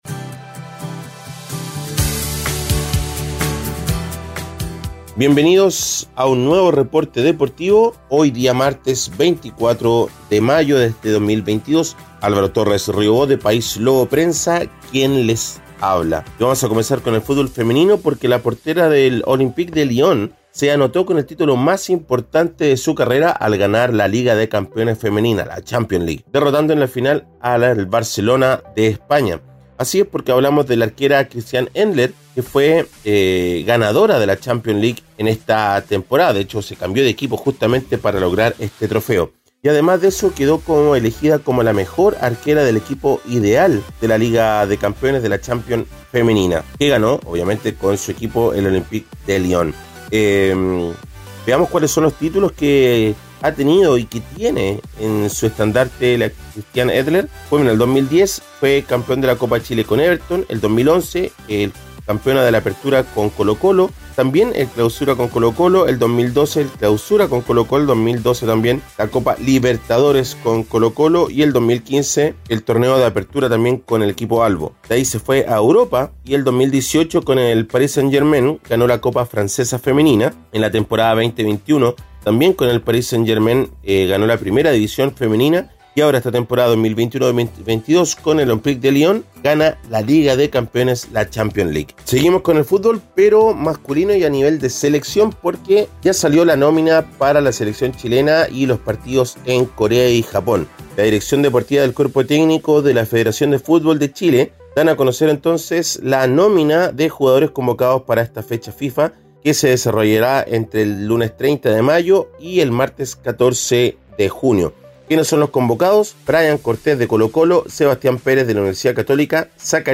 Reporte Deportivo ▶ Podcast 24 de mayo de 2022